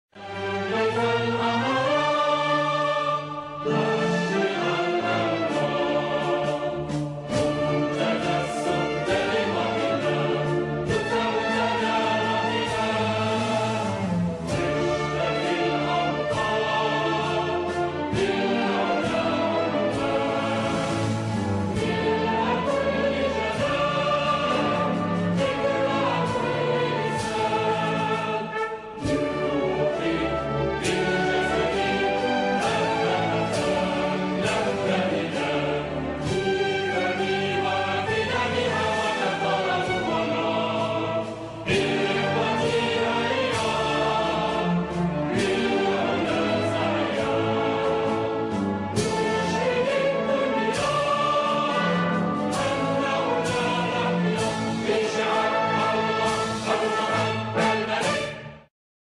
Старинная вокальная версия гимна